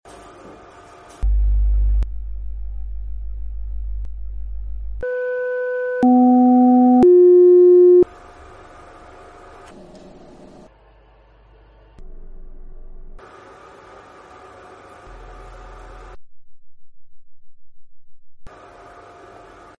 WATER droplets vibrated by SOUND sound effects free download
🎶Sine wave frequencies 100-200Hz.